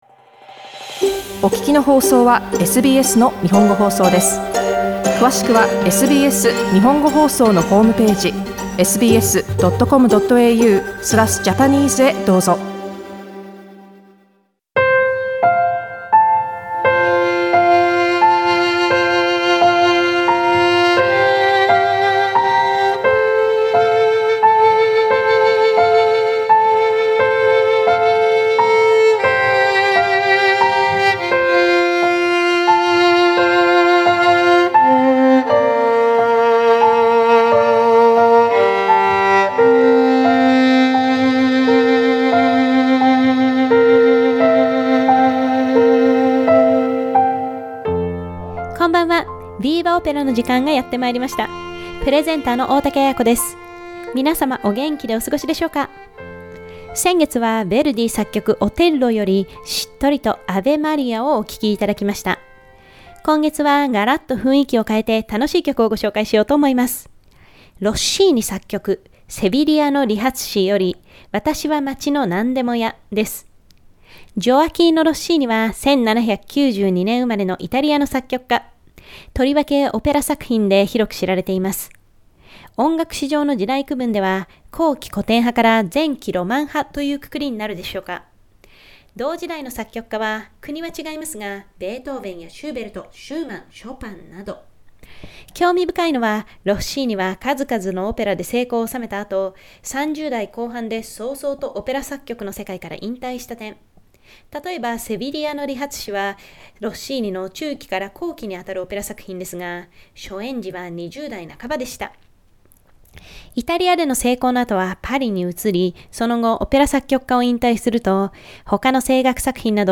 Opera #1) VIVA! Opera is a monthly content broadcast on the last Tuesday of each month.